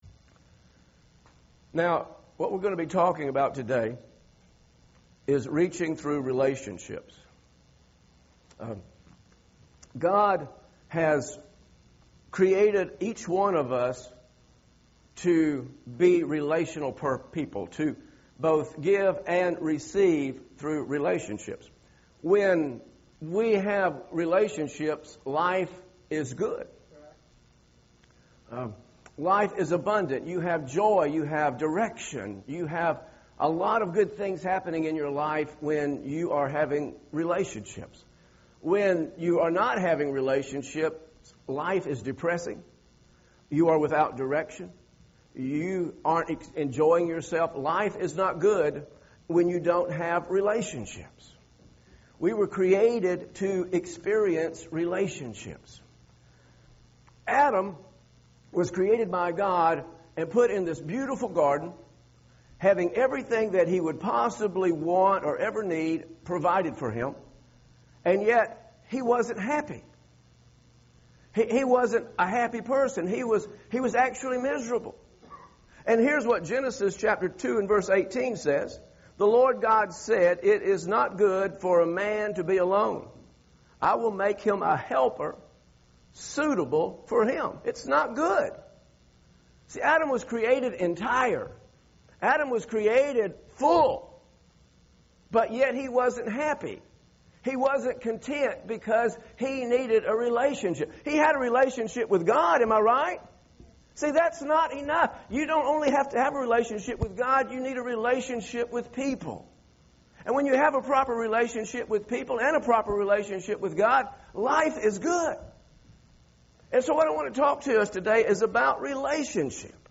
Reaching Through Relationships sermon video audio notes.